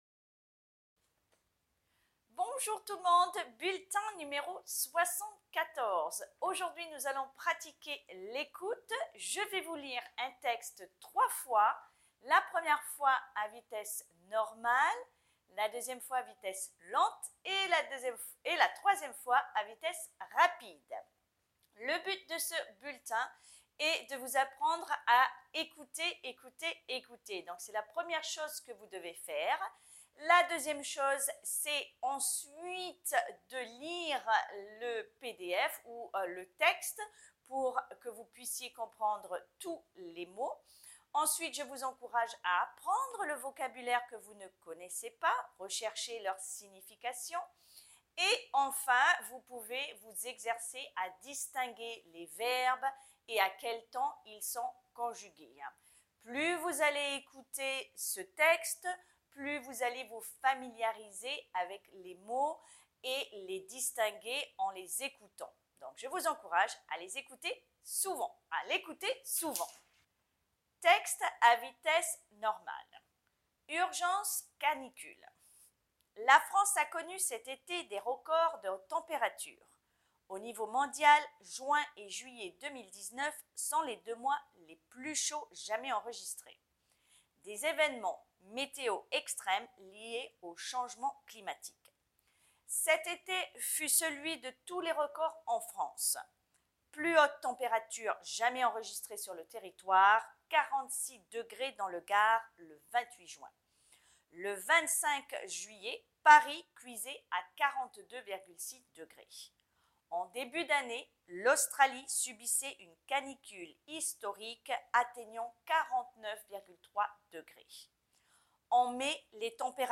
Texte à trois vitesses à écouter
Commencez, avec la vitesse normale, ensuite la vitesse lente (pour vous familiariser avec tous les mots) et enfin écouter à vitesse rapide pour vous habituez à ce rythme.